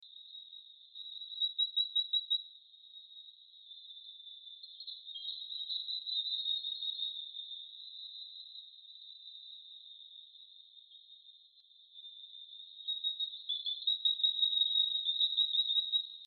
Coquí Melodioso